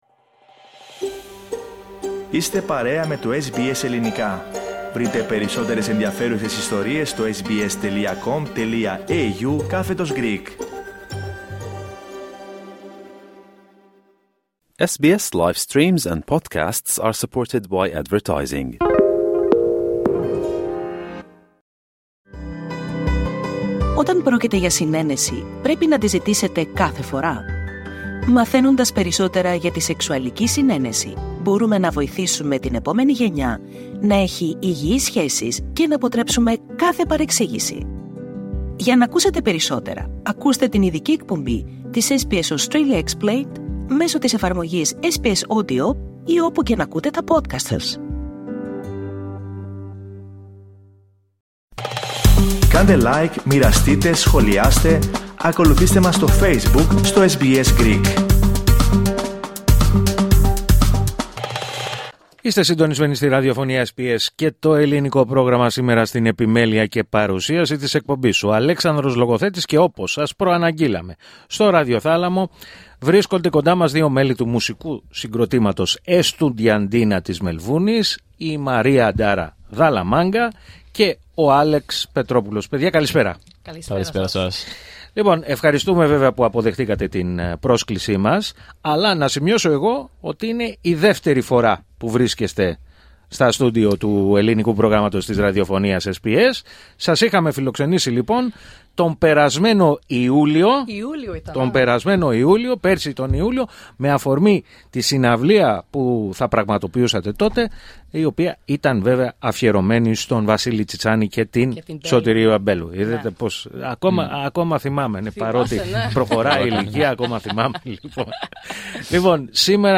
Το μουσικό συγκρότημα Εστουντιαντίνα της Μελβούρνης, παρουσιάζει στις 9 Μαρτίου, το πρώτο μουσικό άλμπουμ του. Με αυτήν αφορμή, βρέθηκαν στο ραδιοθάλαμο του Ελληνικού Προγράμματος της ραδιοφωνίας SBS, δύο μέλη του συγκροτήματος, και πιο συγκεκριμένα